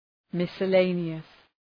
{,mısə’leınıəs}